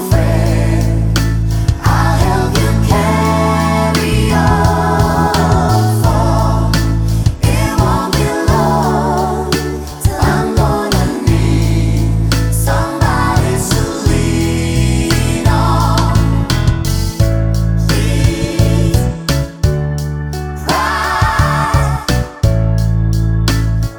no Backing Vocals Soundtracks 4:19 Buy £1.50